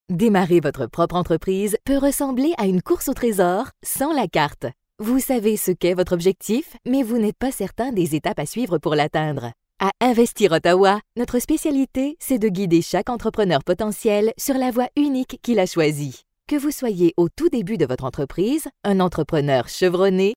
French Canadian female voice over French Canadian voice overs.